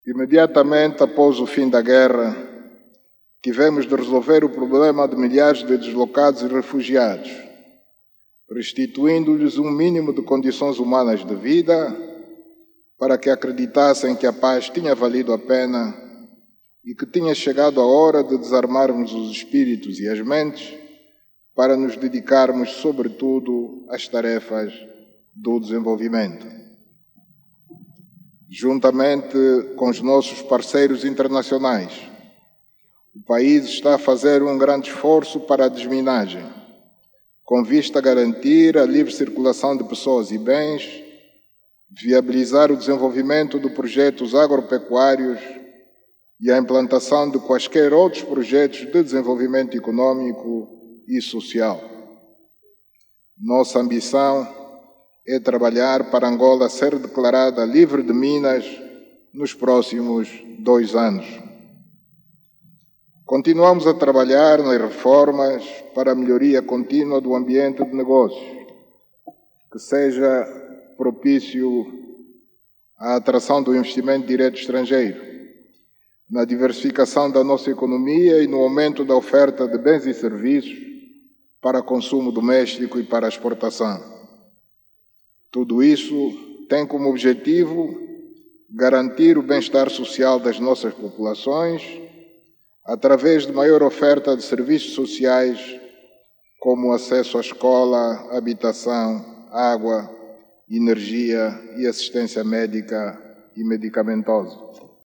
O Chefe de Estado fez estes pronunciamentos durante o seu discurso à Nação, na Praça da República, em Luanda, onde decorre o acto central das comemorações dos 50 anos da Independência Nacional.